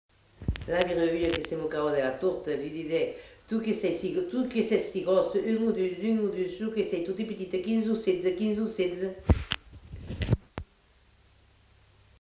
Aire culturelle : Médoc
Lieu : Saint-Trélody (lieu-dit)
Genre : forme brève
Type de voix : voix de femme
Production du son : récité
Classification : mimologisme